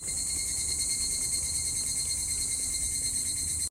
蝉の声sound of Higurashi cicada / かなかな ひぐらし